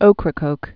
(ōkrə-kōk)